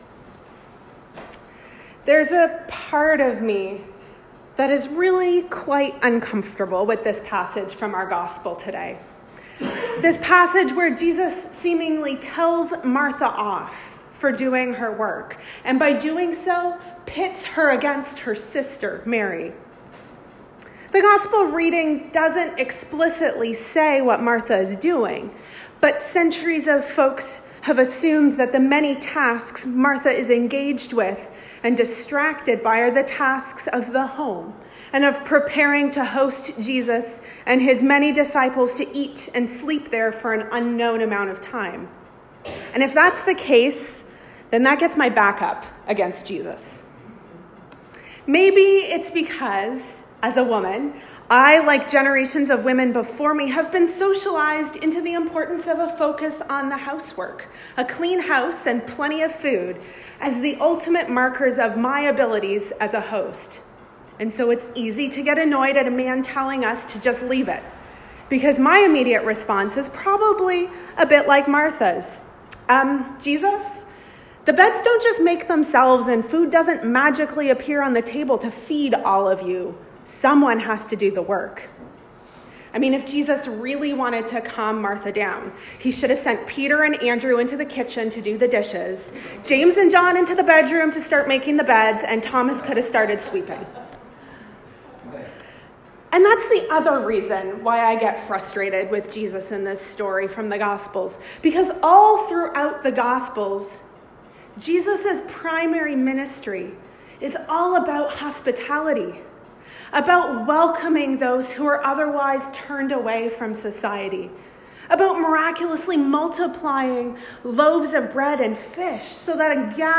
Sermons | Parish of the Valley
Recorded at St George's, South Alice.